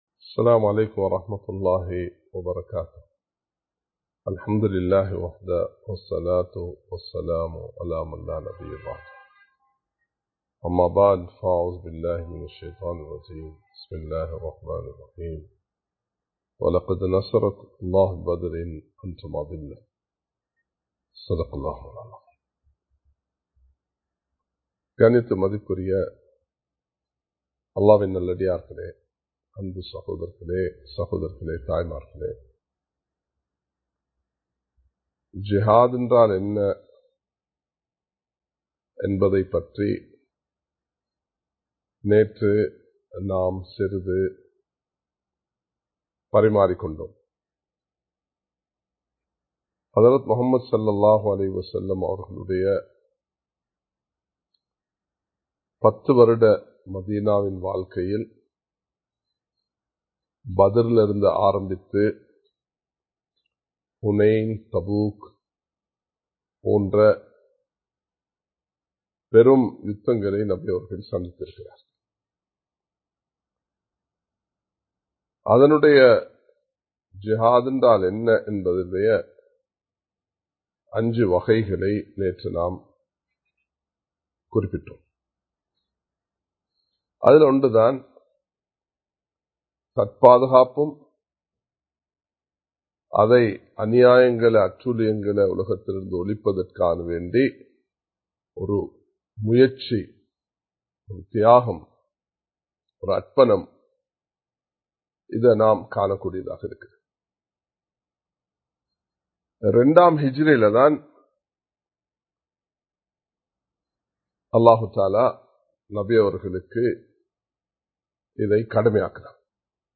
பத்ர் சொல்லும் பாடம் (பகுதி 01) | Audio Bayans | All Ceylon Muslim Youth Community | Addalaichenai
Live Stream